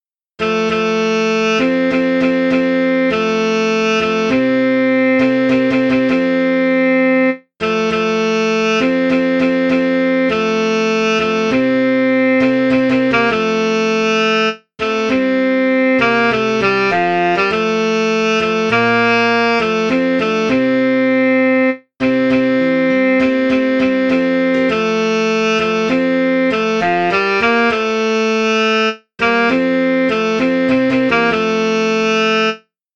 Einzelstimmen (sopran/alt/tenor/bass/tutti)